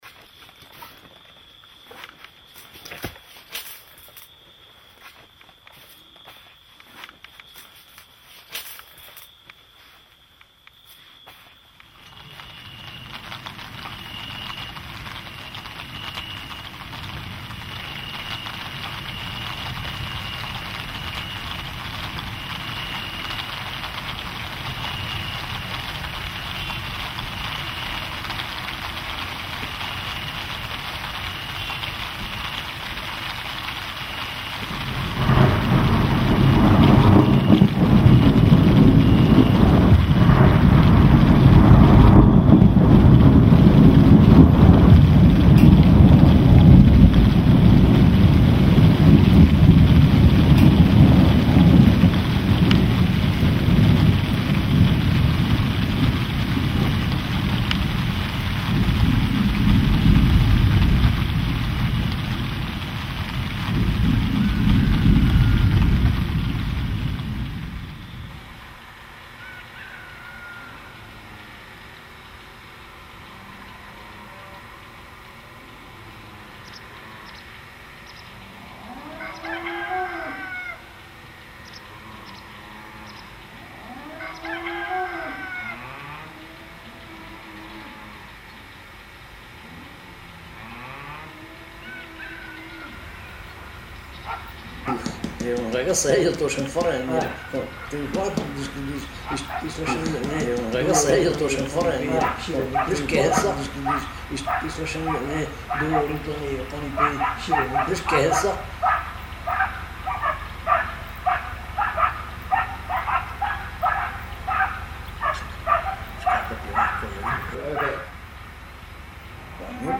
sentire a livello uditivo i suoni della natura, le sue manifestazioni
sonore come la pioggia e i tuoni, i latrati dei cani, il parlare degli
uomini e delle donne, le campane delle chiese, i rosari, i canti, lo